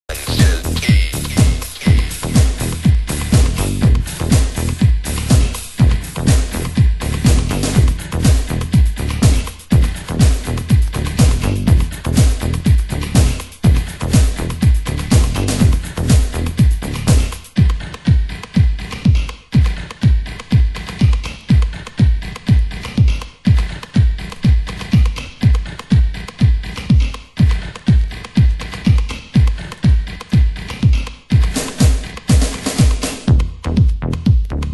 ○現役アーティストによるリミックスで、ロック〜エレクトロとも合いそうなミックスへ！！